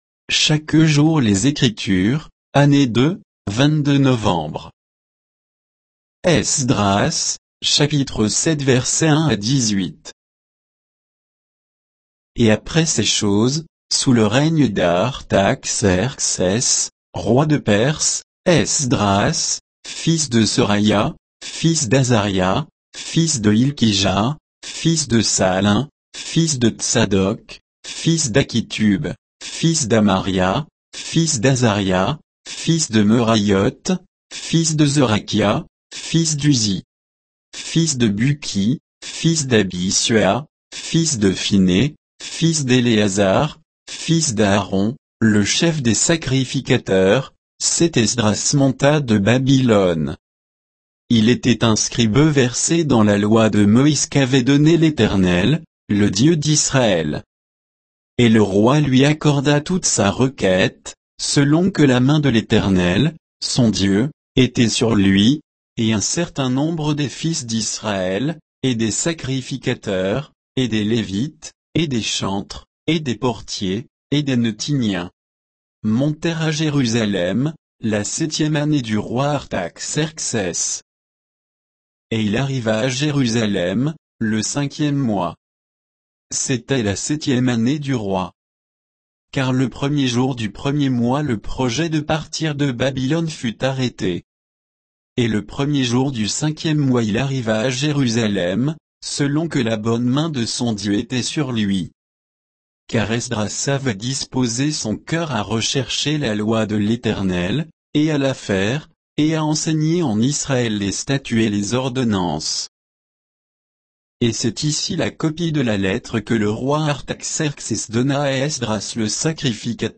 Méditation quoditienne de Chaque jour les Écritures sur Esdras 7